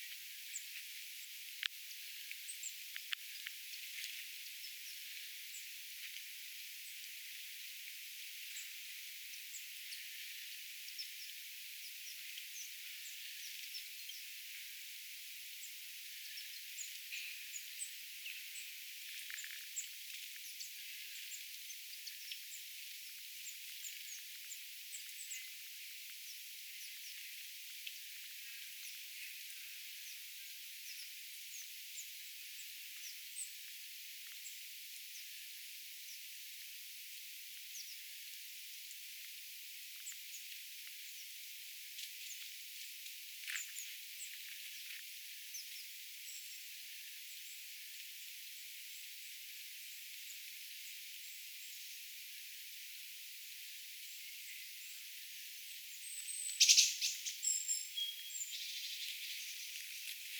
ja ne pitivät sitä erikoista ruokailuääntä,
sini- ja talitiaiset ruokailevat hiljaisesti,
paikalle saapuu närhi
sini_ja_talitiaiset_ruokailevat_hiljaisesti_paikalle_sapuu_narhi.mp3